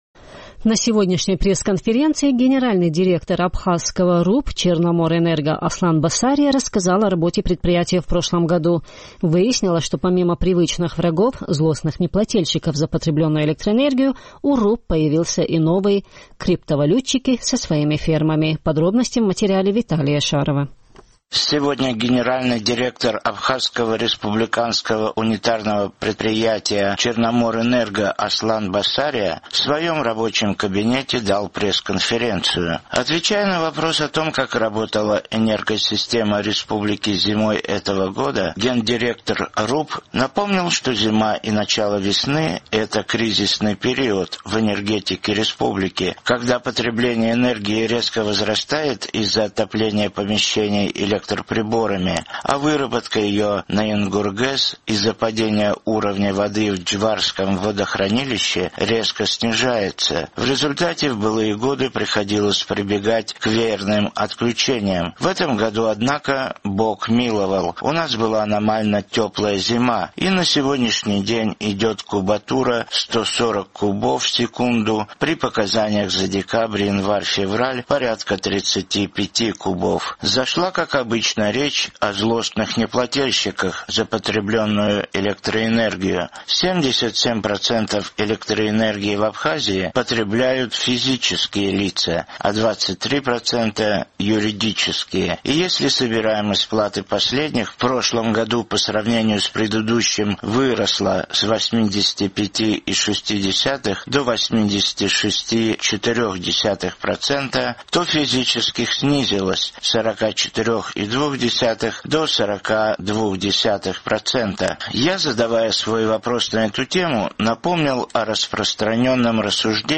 На сегодняшней пресс-конференции